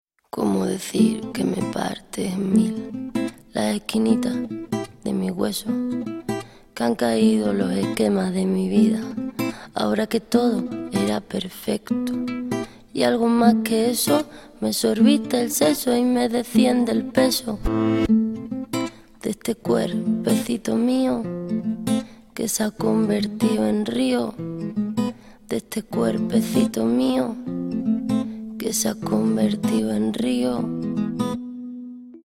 гитара
женский вокал
спокойные
чувственные
Latin Pop
Чувственная песня из рекламы вишневого сока "Я"